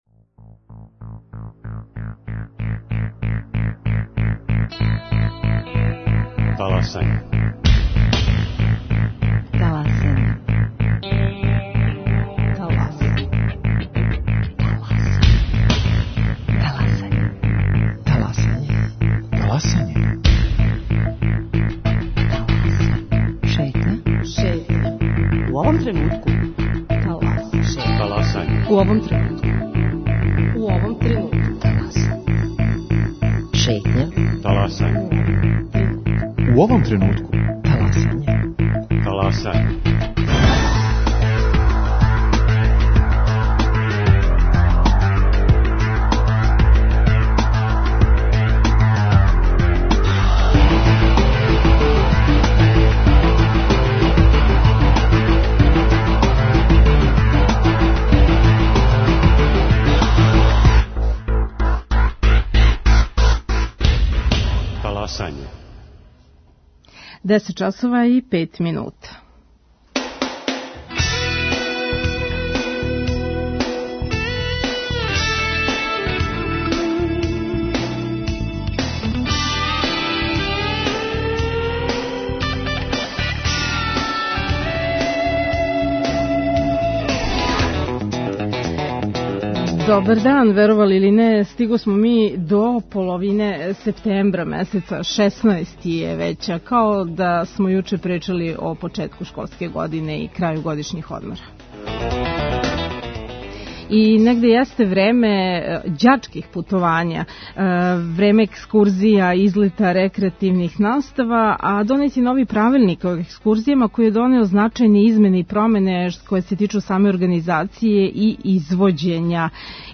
О новим правилима за извођење екскурзија разговарамо са Весном Недељковић, помоћницом министра просвете.